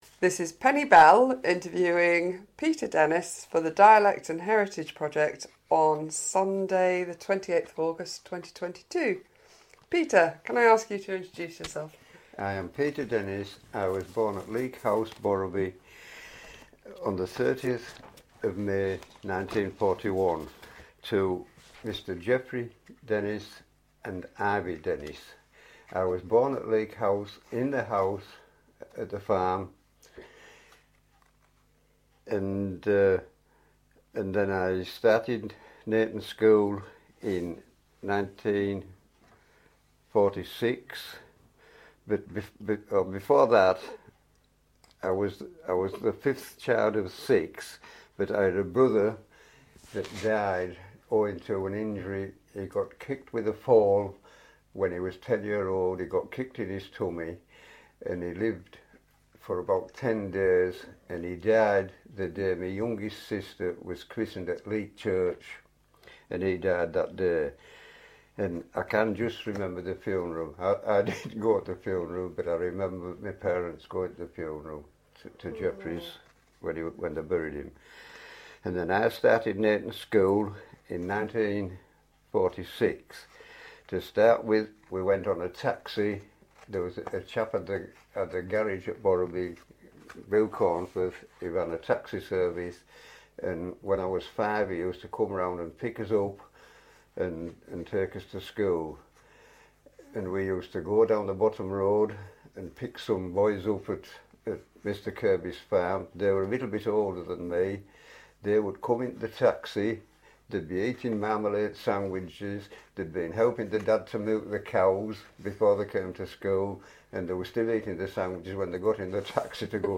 Digital recording of oral history interview conducted with named interviewee (see item title) as part of National Lottery Heritage funded, "Dialect and Heritage" Project.